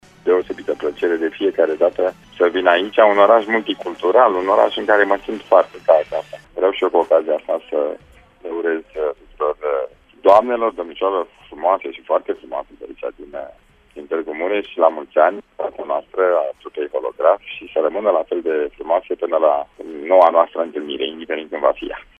Evenimentul, care a început cu o oră în urmă la Sala Polivalentă, este organizat de Primăria Municipiului Tîrgu-Mureş.
Solistul trupei Holograf, Dan Bitman, a declarat, că se întoarce cu drag la Tîrgu-Mureș: